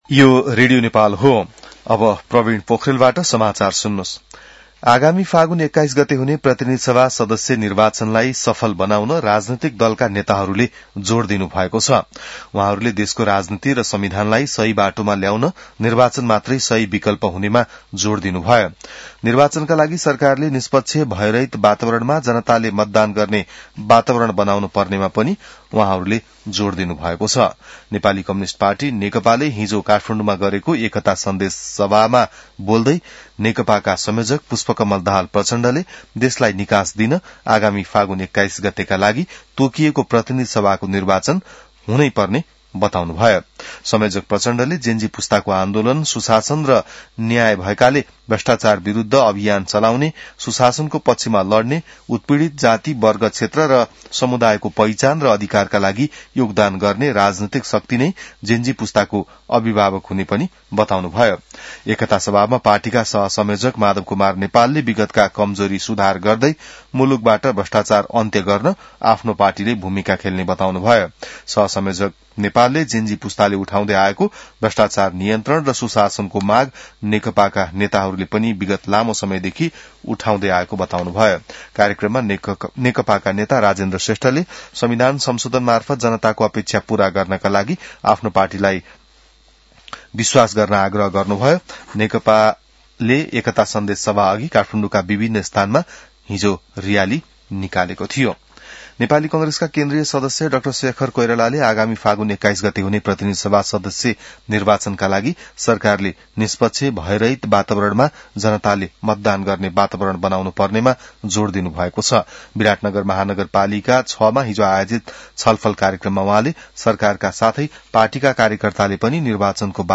बिहान ६ बजेको नेपाली समाचार : ६ पुष , २०८२